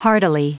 Prononciation du mot hardily en anglais (fichier audio)
Prononciation du mot : hardily